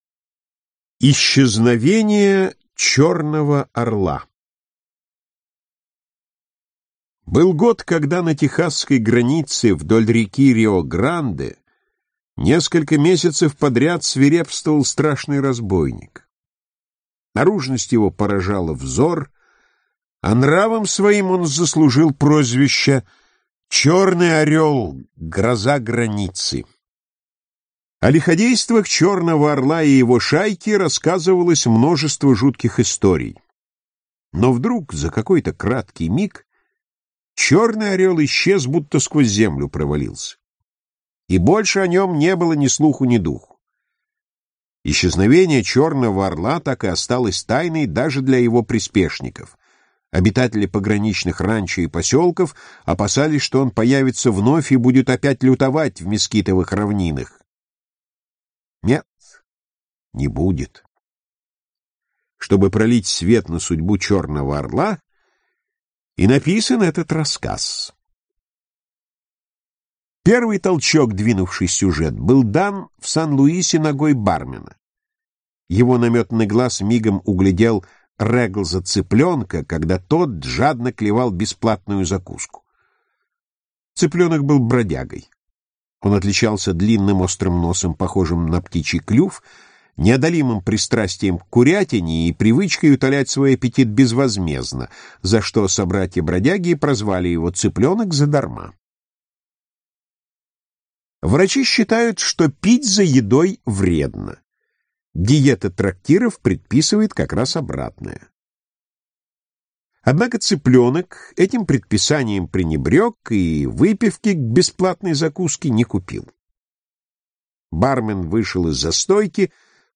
Аудиокнига Новеллы. Вып. 1 | Библиотека аудиокниг